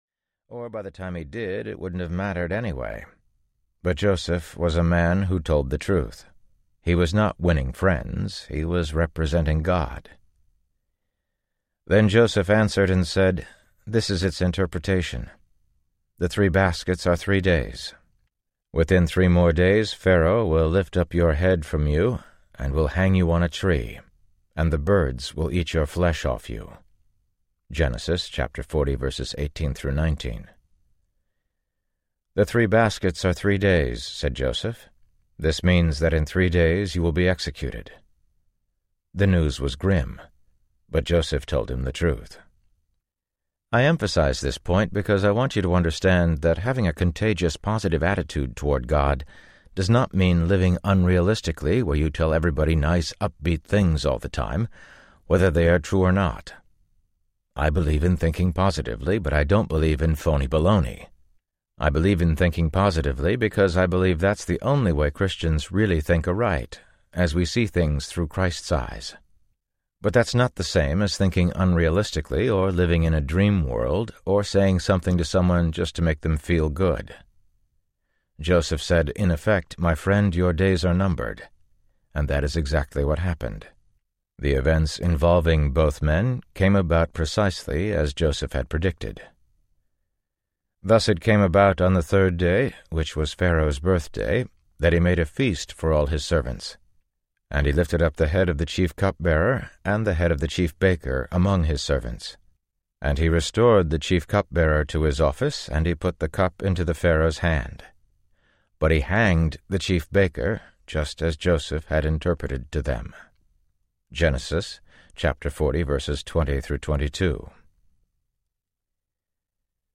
Great Lives: Joseph Audiobook
Narrator
8 Hrs. – Unabridged